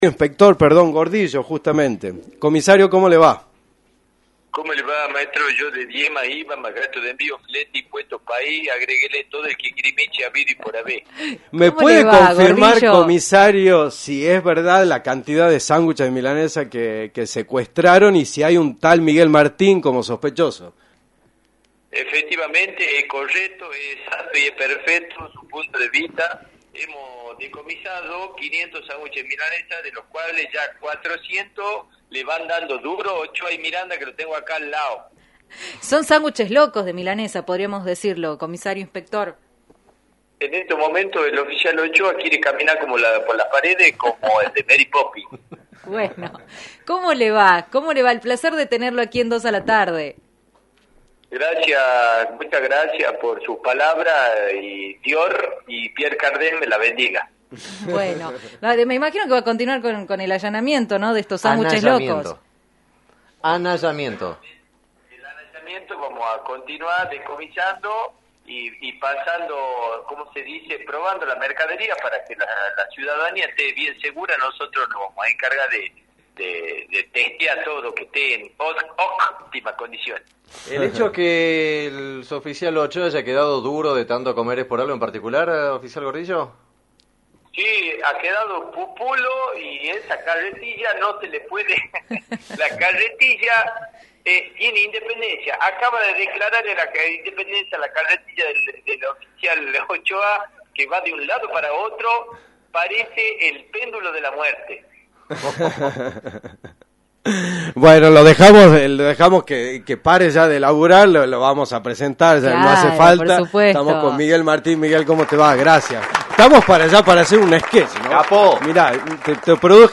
En diálogo con los micrófonos de la emisora, el actor impregnó el aire radial con su personaje venerado y multipremiado del Oficial Gordillo. Además, el actor reflexionó sobre aspectos singulares de su vida y su carrera artística.